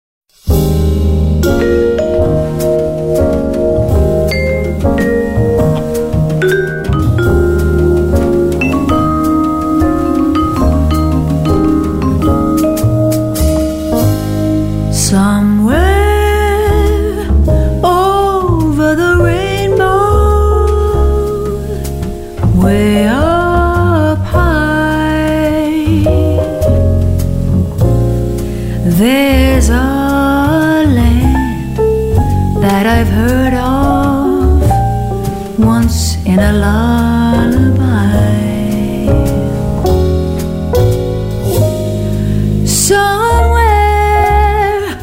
vocal&bass
vibraphone
piano
Recorded at Avatar Studio in New York on March 23 & 24, 2011